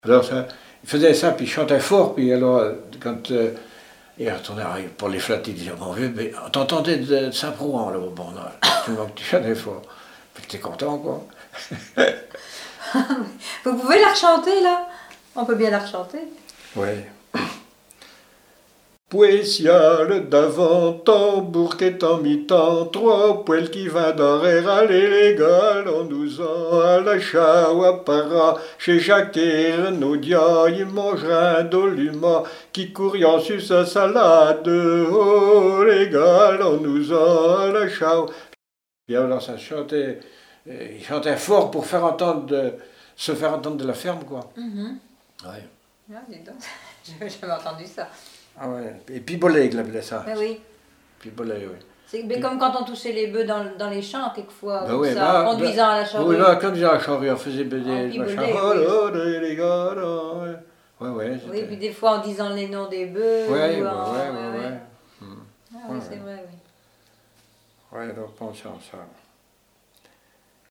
chanteur(s), chant, chanson, chansonnette
Genre brève
Répertoire de chants brefs pour la danse
Pièce musicale inédite